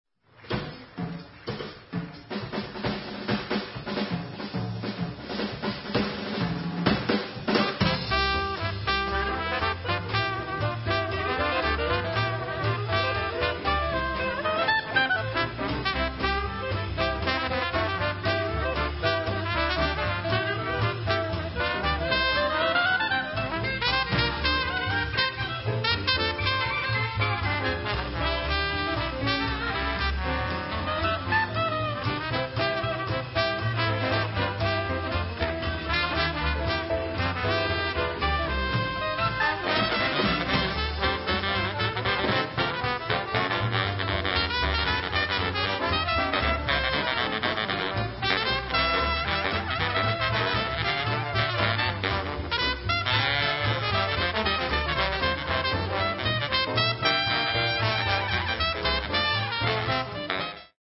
Recorded Live at NEWORLEANS JAZZ ASCONA il 5 luglio, 2001